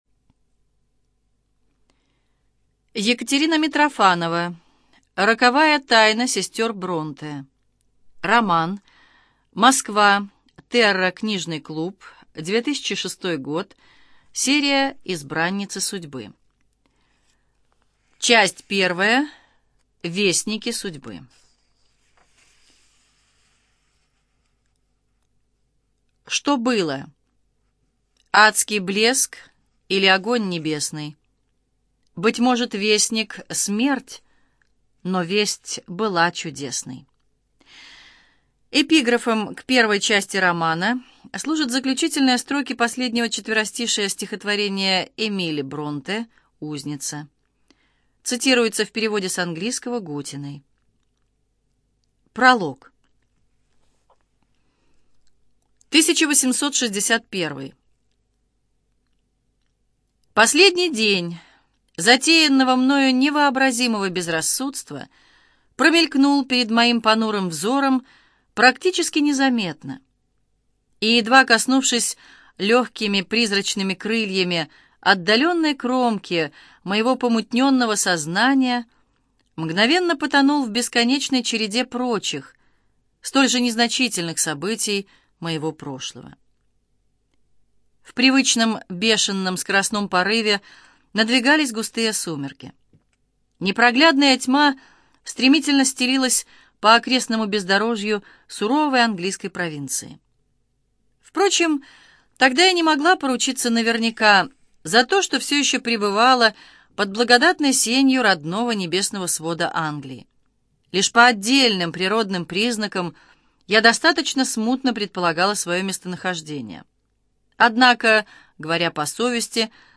ЖанрИсторическая проза, Биографии и мемуары, Документальные фонограммы
Студия звукозаписиЛогосвос